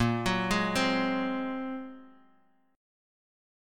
Listen to A#7sus2#5 strummed